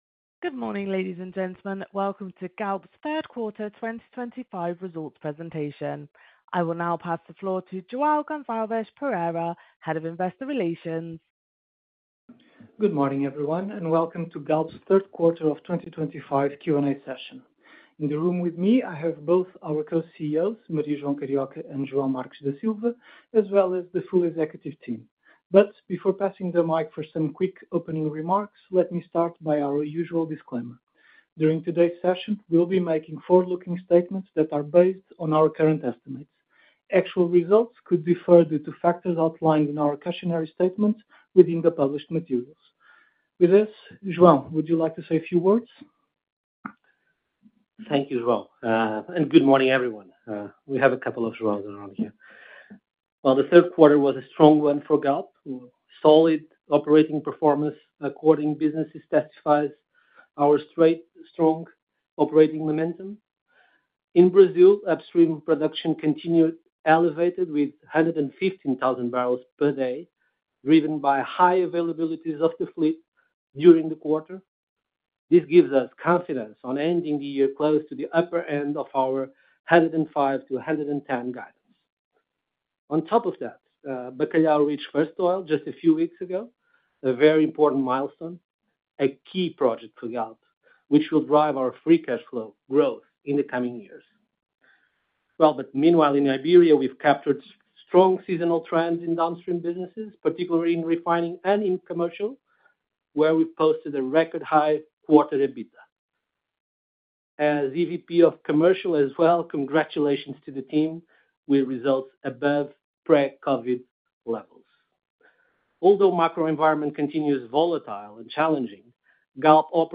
Ouvir a conference call - 1Q - 2025
Galp 3Q 2025 Results Presentation.mp3